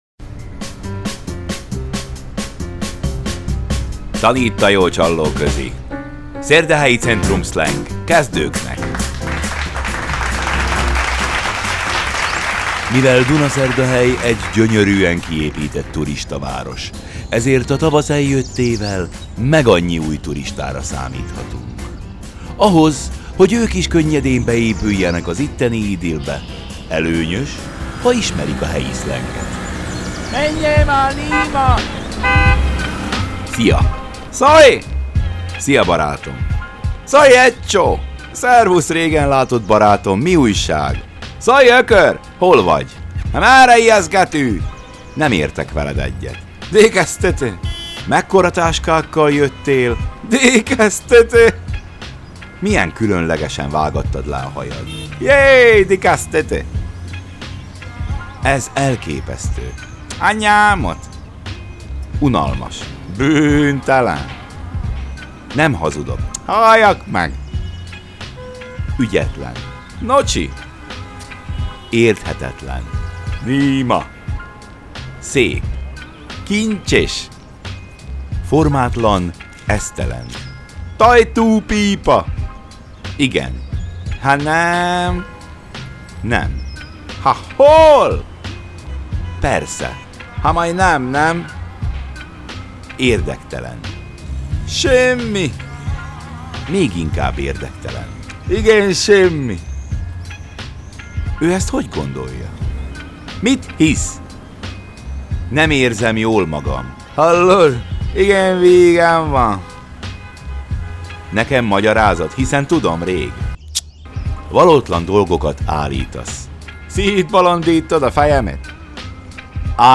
Szaval a jó öreg csallóközi Tanítt a jó csallóközi: Szërdehelyi cëntrumszlëng May 08 2023 | 00:03:14 1x 00:00 / 00:03:14 Subscribe Share